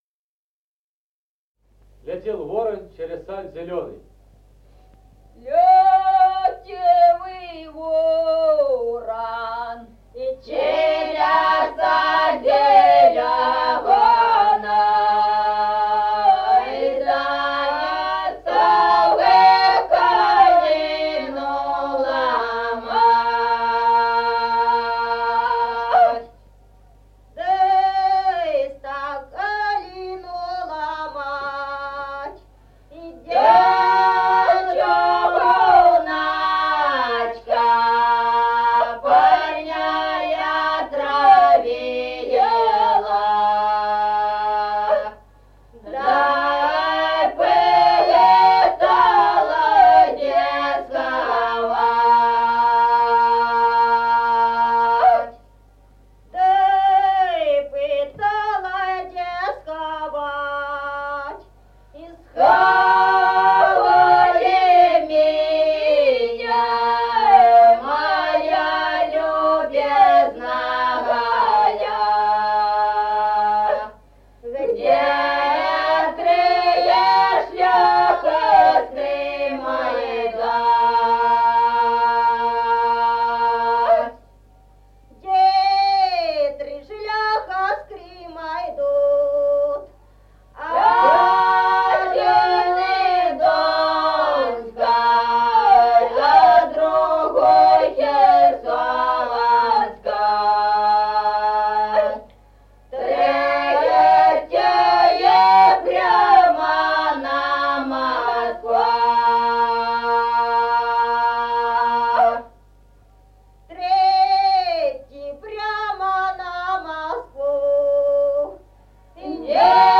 Музыкальный фольклор села Мишковка «Летел ворон», лирическая.